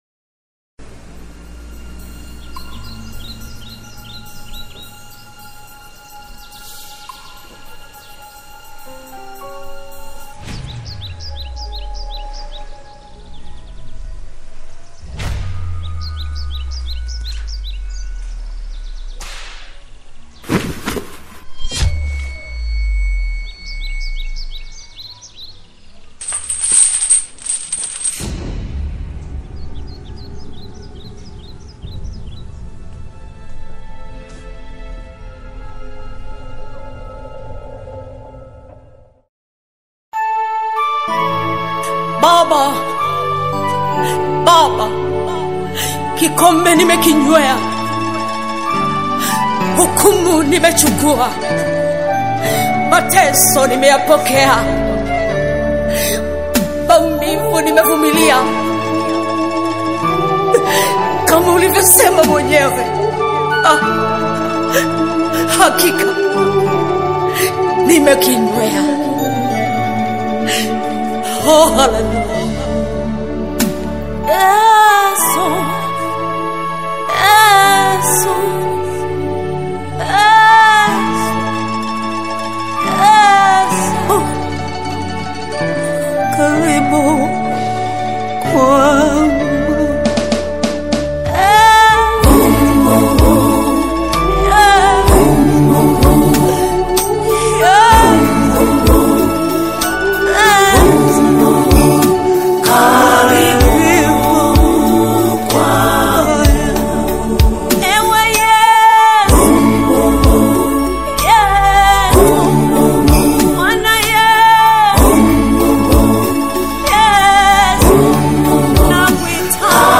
Christian gospel music